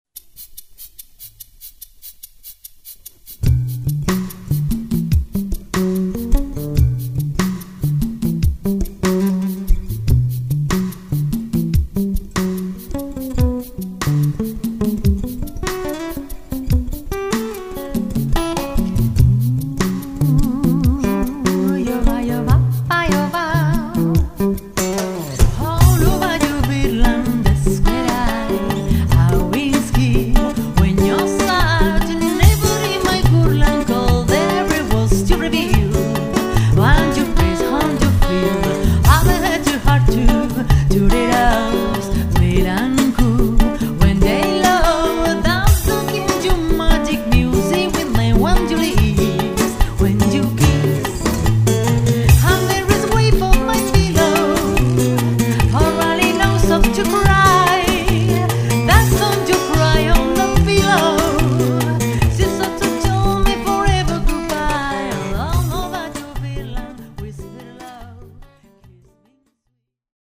Voz y Coros
Guitarras
Studio